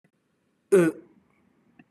/ eu /